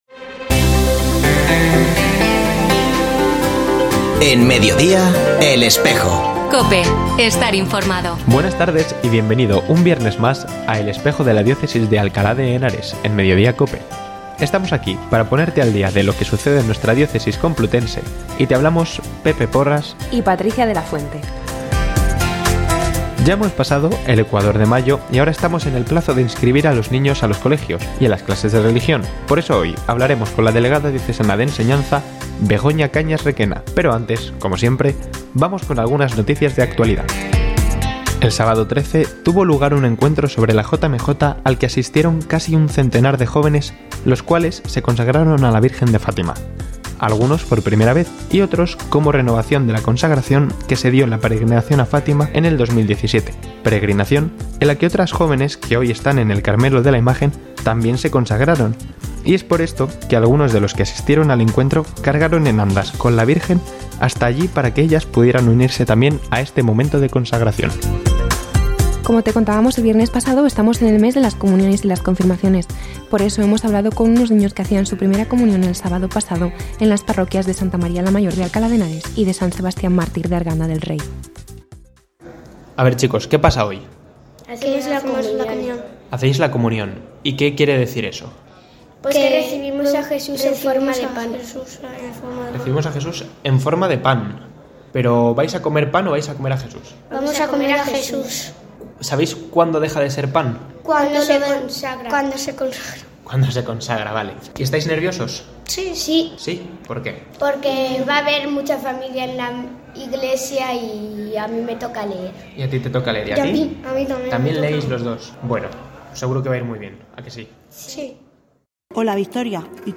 Ofrecemos el audio del programa de El Espejo de la diócesis de Alcalá emitido el viernes 19 de mayo de 2023 en radio COPE. Recordamos que este espacio de información religiosa de nuestra diócesis puede escucharse en la frecuencia 92.0 de FM, los viernes de 13.33 a 14 horas.